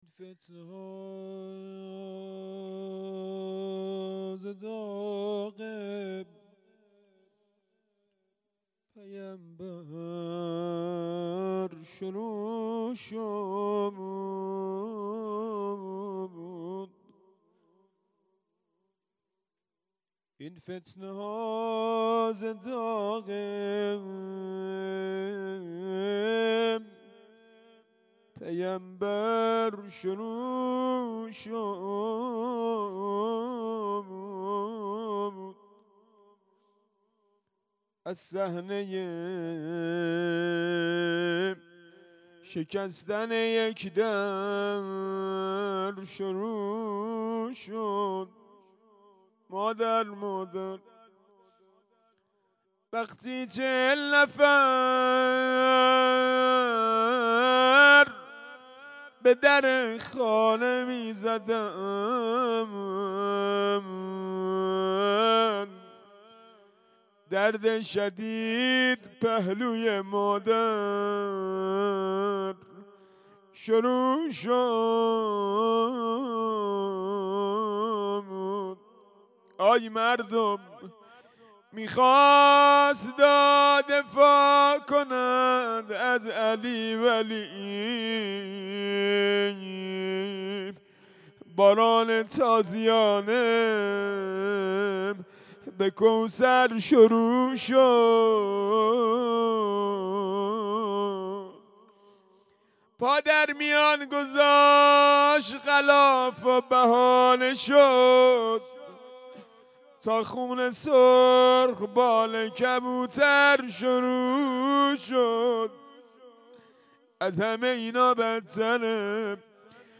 اولين شب عزاداري در غمخانه حضرت رقيه سلام الله عليها
روضه